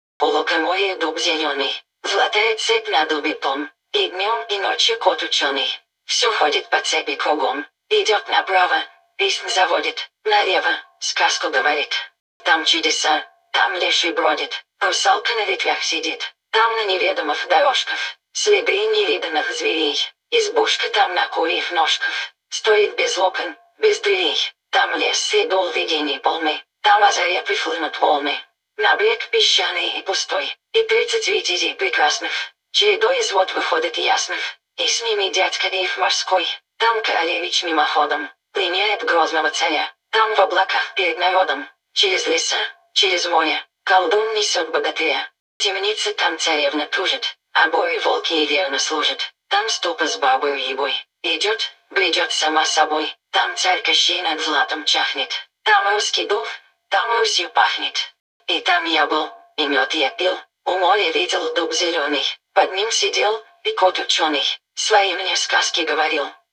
so-vits-svc
Fallout_4 / English /F4_F_DLC01RobotCompanionFemaleDefault_Eng /OLD /G_34000 (Rus).wav